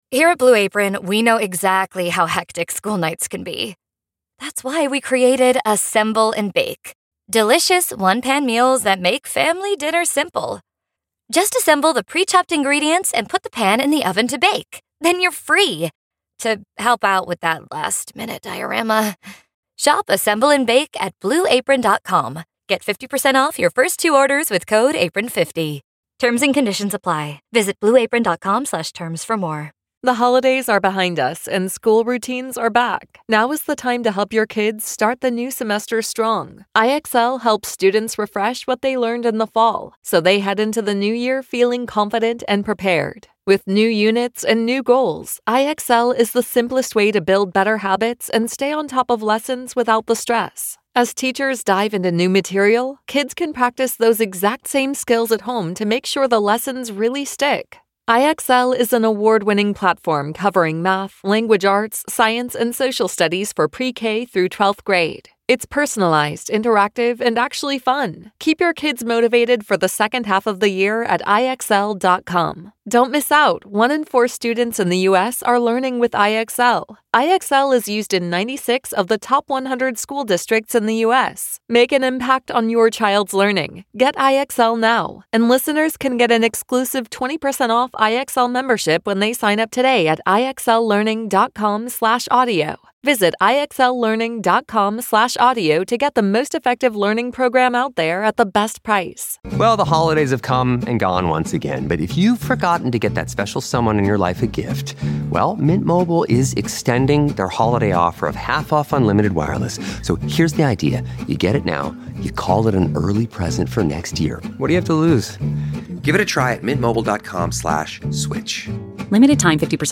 We've got Nate Smith here at the Gila River Country Club and we've got his take on the Morgan Wallen chair incident, touring with Morgan, his new EP and More!